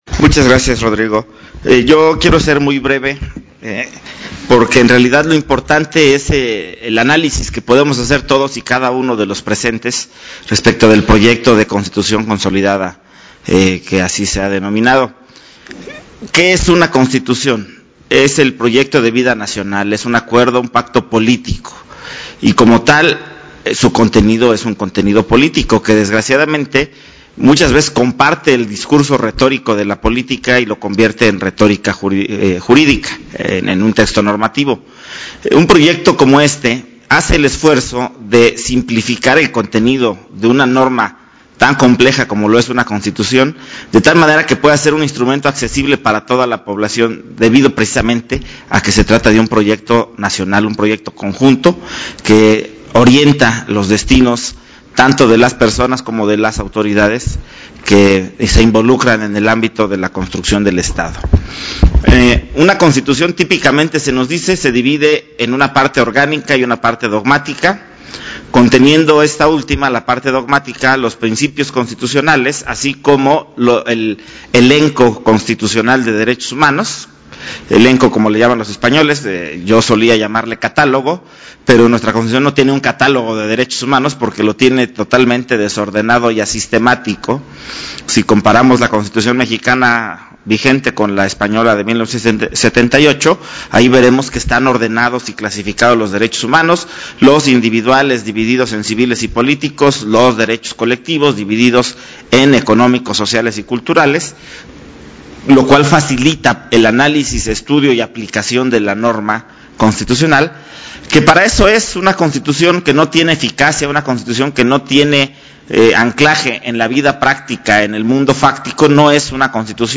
Durante la presentación del proyecto “Hacia la reordenación y consolidación de la Constitución Política de los Estados Unidos Mexicanos”, el Magistrado del TEDF aseguró que la Constitución Política de México es el proyecto de vida nacional, un acuerdo político que orienta los destinos de las personas y de las autoridades para que se involucren en el ámbito de la construcción del Estado.
Escucha un fragmento del discurso del magistrado presidente del TEDF, Armando Hernández. dale click al archivo de aquí abajo:1